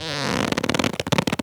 foley_leather_stretch_couch_chair_26.wav